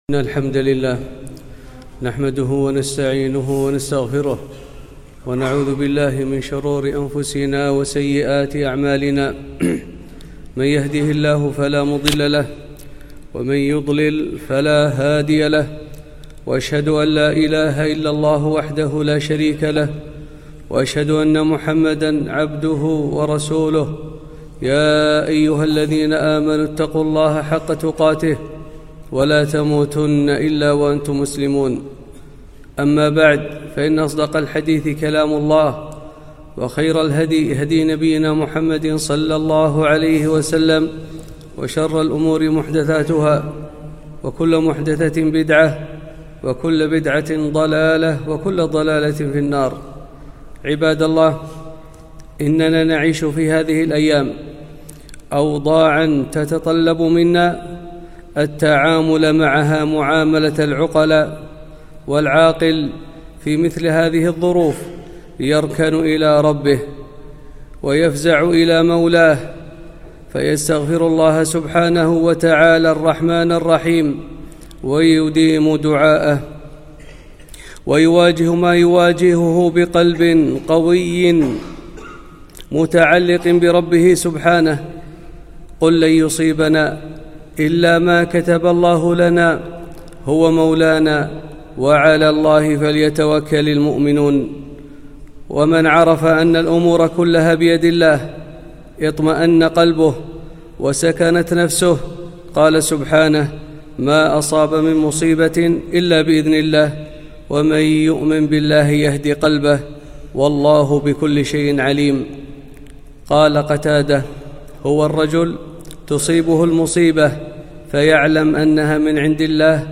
خطبة - فالله خير حافظا وهو أرحم الراحمين